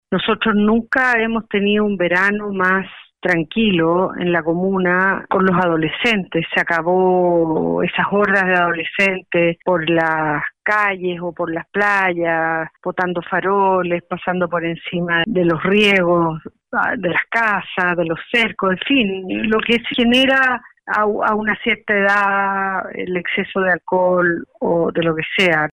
Resultados que también destacó la concejala Josefina Sutil, al asegurar que, hasta la fecha, la ordenanza ha traído beneficios en torno a la seguridad y el orden en la comuna.